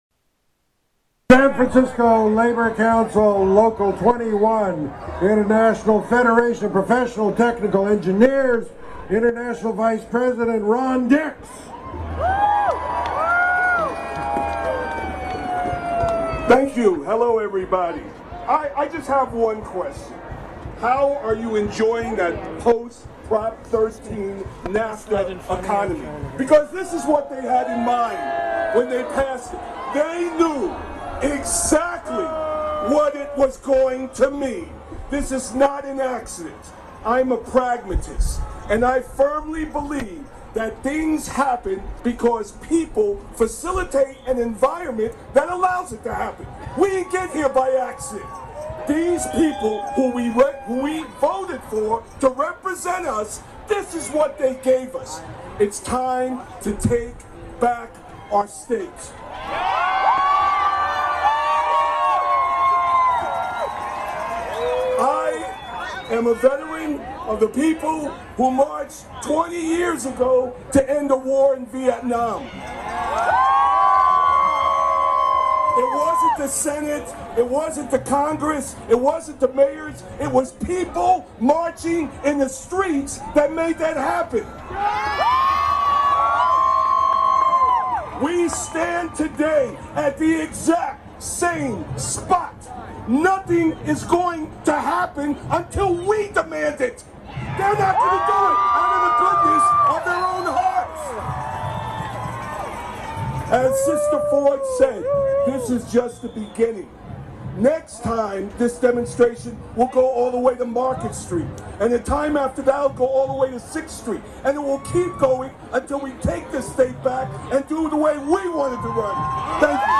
Hear 25 minutes of audio as students, workers, and other activists rallied in an organized, permitted rally at the Civic Center San Francisco March 4.
The form and substance of the action was similar to many other big rallies at that site, with labor leaders, teachers, other workers, and students giving short, fiery speeches in a contained environment to a large, cheering crowd. Students held side rallies on the steps of City Hall and in-between and a drumming circle in the back.